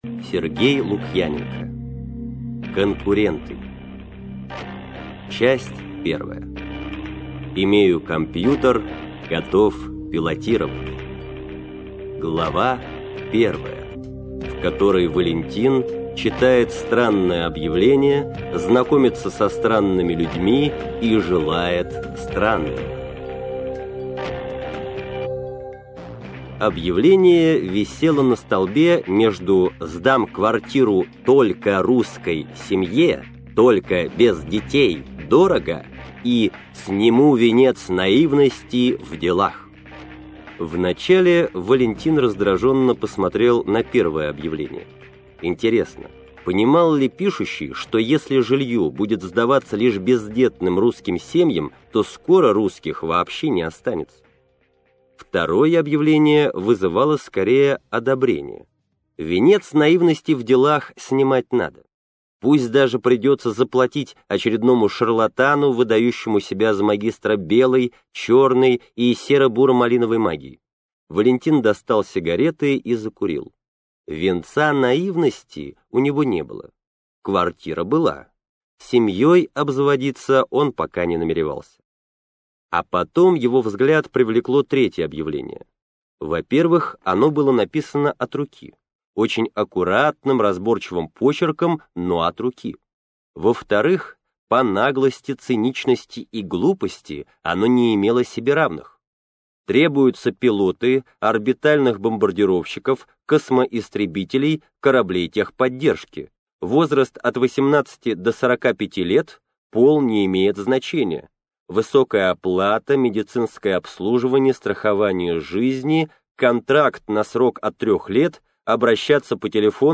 Аудиокнига Конкуренты - купить, скачать и слушать онлайн | КнигоПоиск